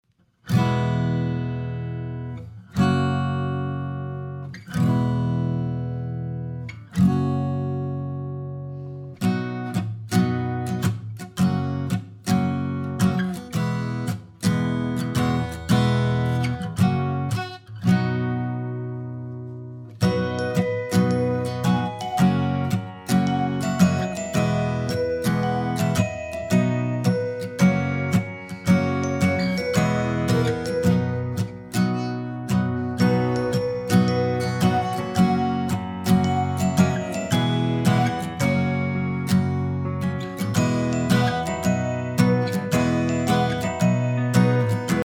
Listen to a sample of the sing-along track.
Mp3 Instrumental Sing Along track;